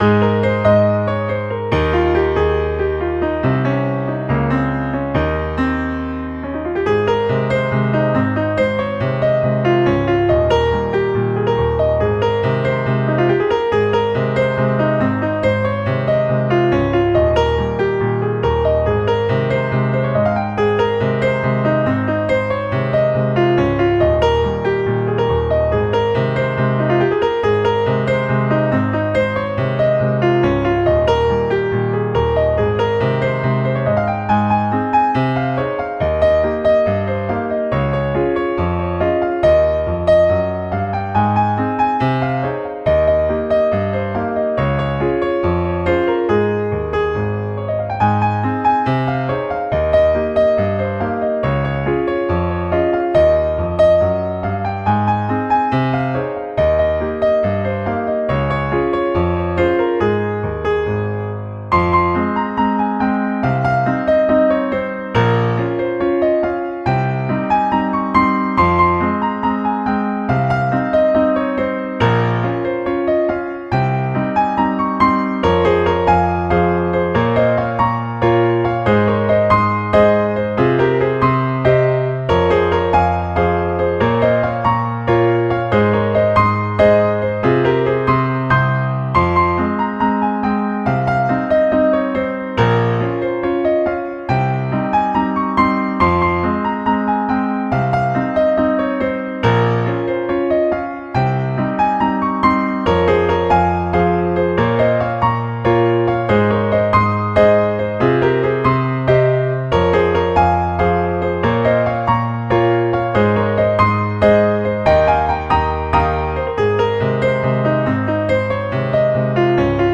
Most of them were written and recorded in just a few hours, and they sound like it.
A little rag:
Please note that it is the computer playing the piano on these, not me.
A-Bit-Ragged.mp3